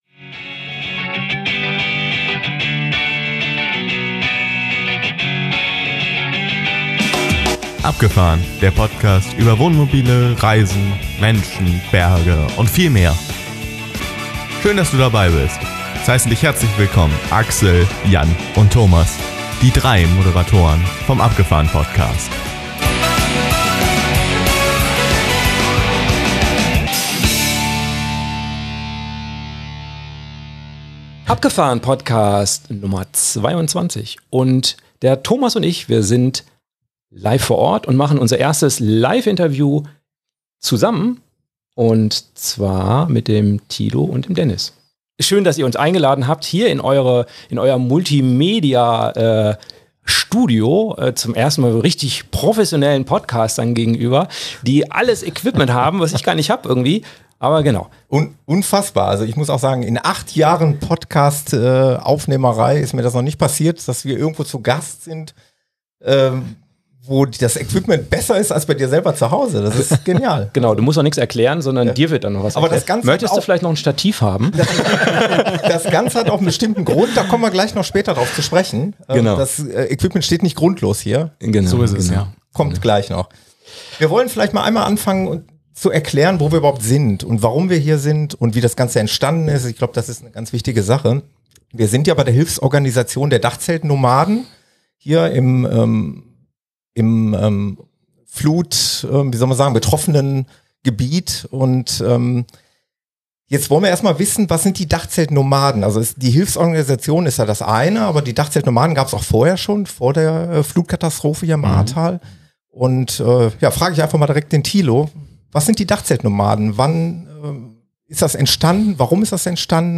Interview - Dachzeltnomaden Hilfsorganisation ~ Abgefahrn-Podcast - Wohnmobile, Camping, Reisen Podcast